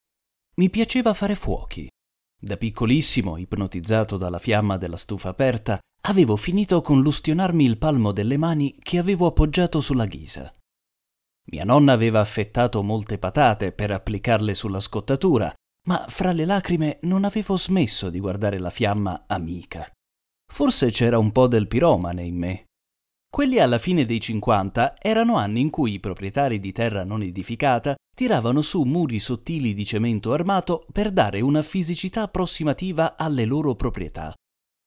Speaker, doppiatore, Voice over artist
Kein Dialekt
Sprechprobe: Sonstiges (Muttersprache):
estratto audiolibro_01.mp3